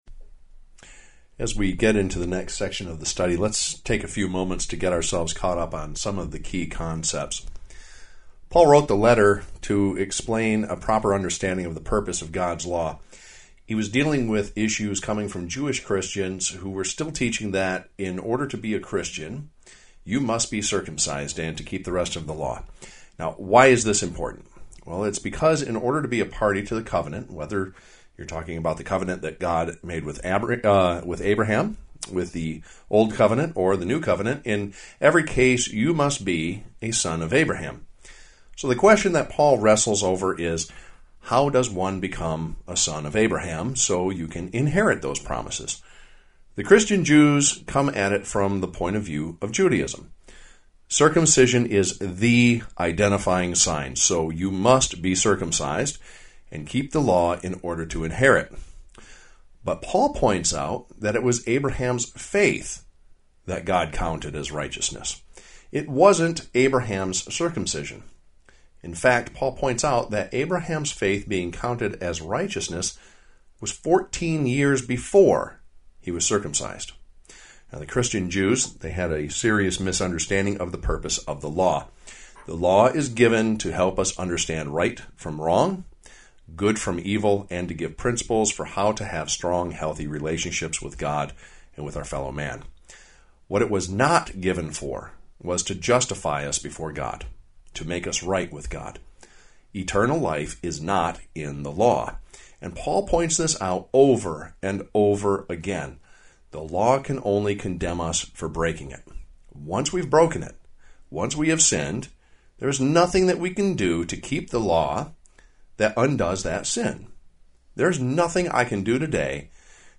Given in Flint, MI